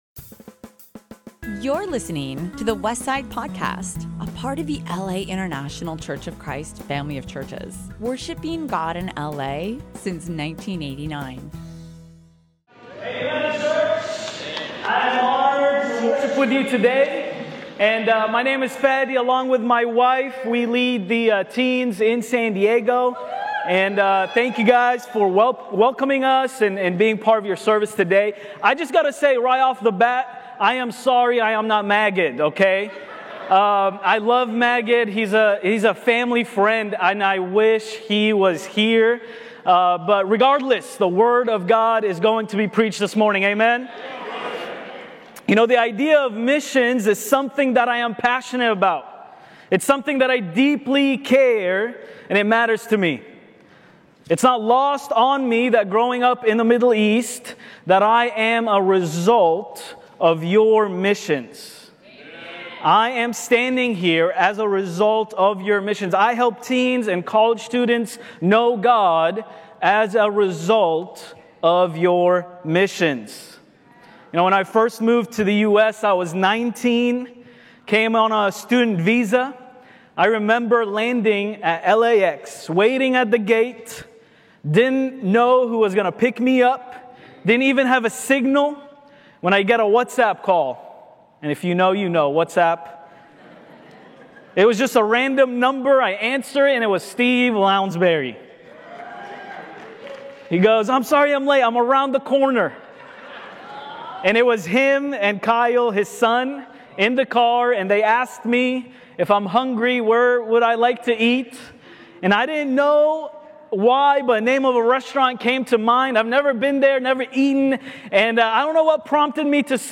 Joint Service with Coastal LA!